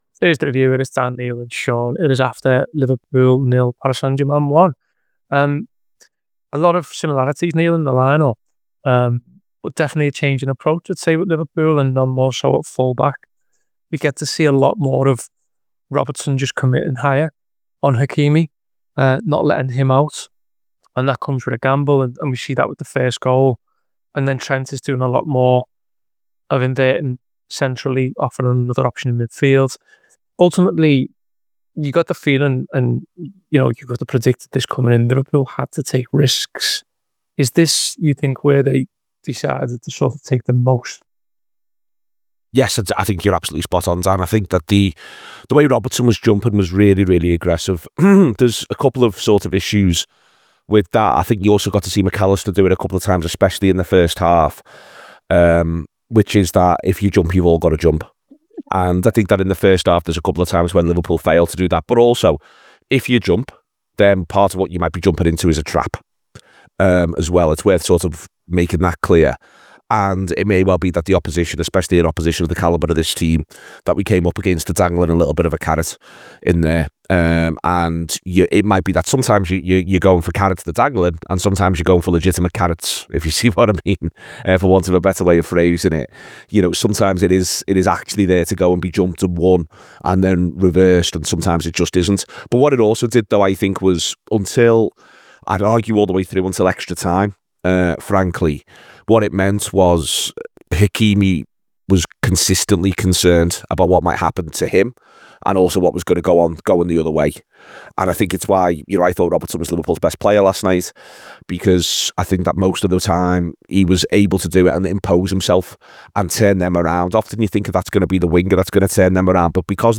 Below is a clip from the show – subscribe to The Anfield Wrap for more review chat around Liverpool 0 Paris Saint-Germain 1…